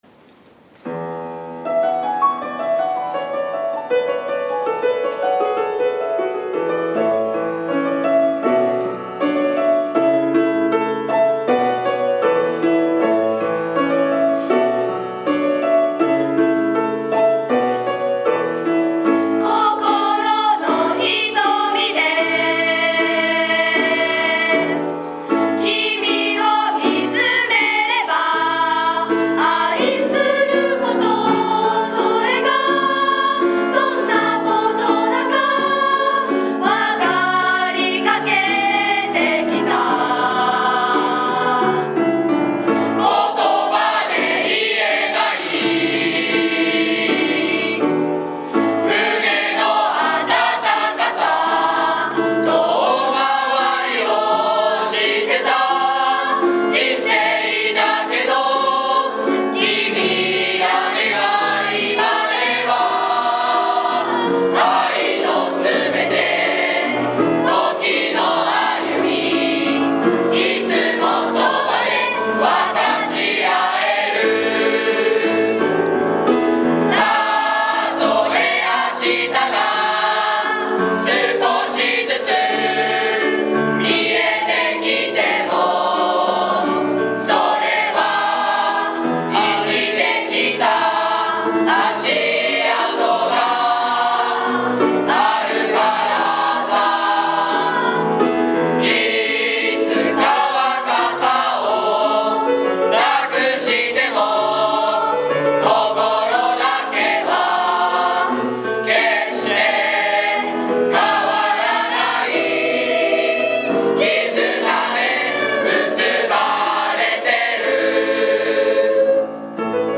2年2組の歌声